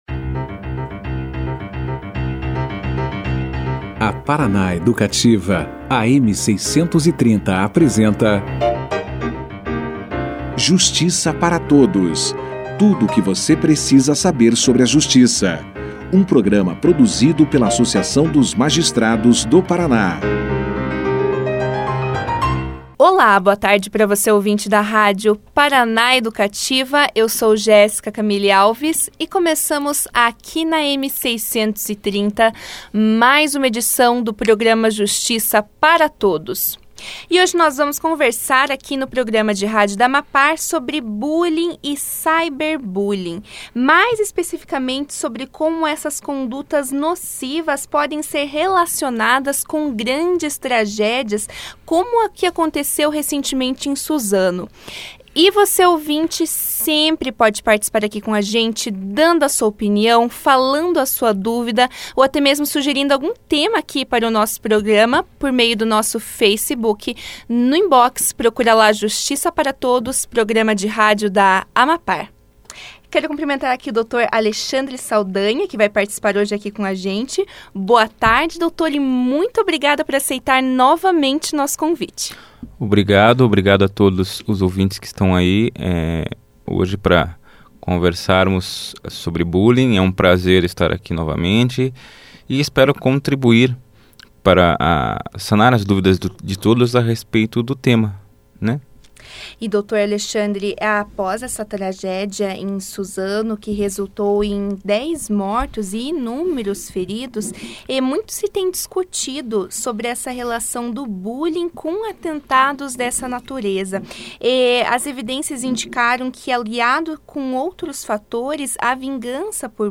Bullying e ciberbullying foram os temas debatidos pelo programa de rádio da AMAPAR, o Justiça para Todos, na quinta-feira (28).